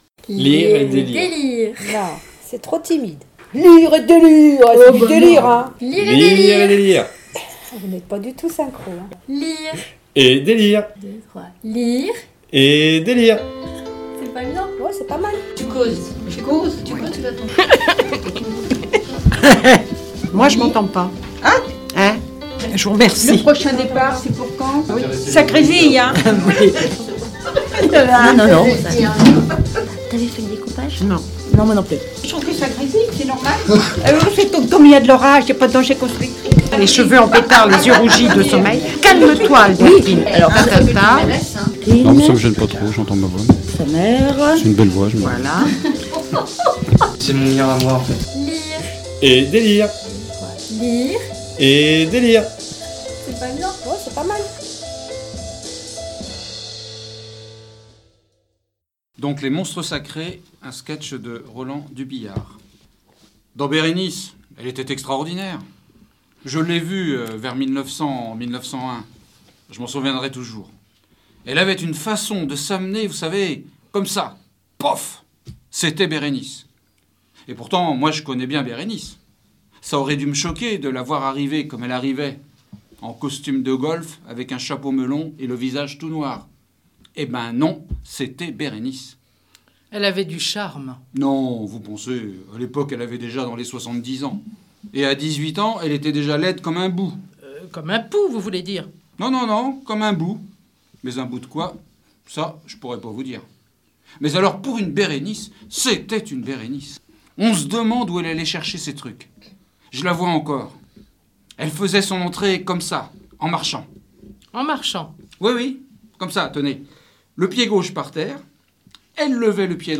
Les Haut Parleurs de l'association "Lire à Saint-Lô" prêtent leurs voix sur MDR dans l'émission "Lire et délire" !